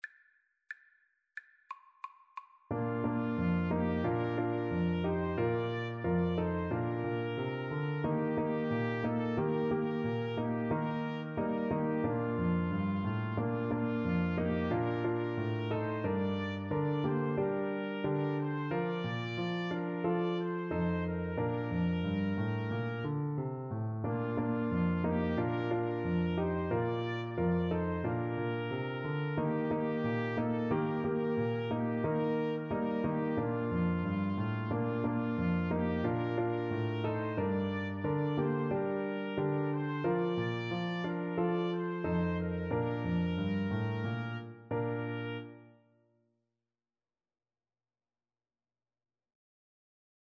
Trumpet 1Trumpet 2
2/2 (View more 2/2 Music)
Steady two in a bar = c. 90